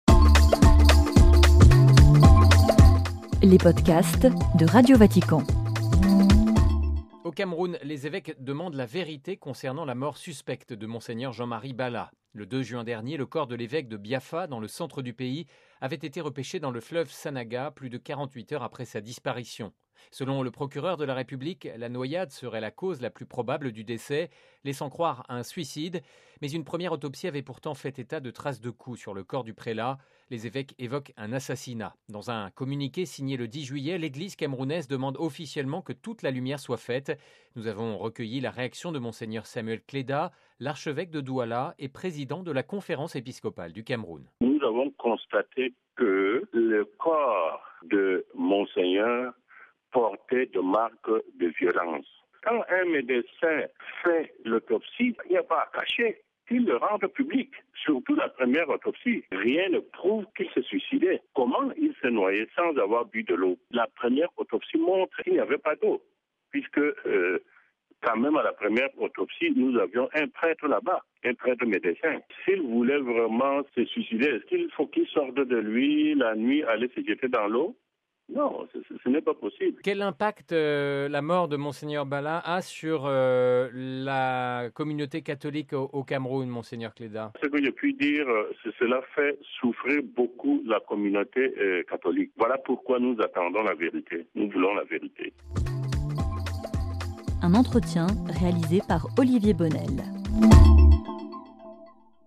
(RV) Entretien - Au Cameroun, les évêques demandent la vérité concernant la mort suspecte de Mgr Jean-Marie Bala.